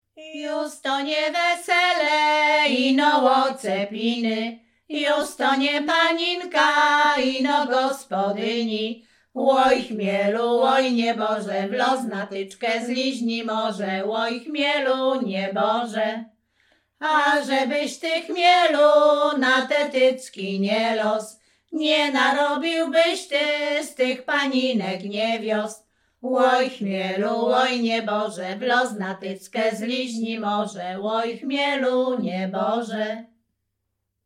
Śpiewaczki z Chojnego
województwo łódzkie, powiat sieradzki, gmina Sieradz, wieś Chojne
Oczepinowa
wesele weselne oczepinowe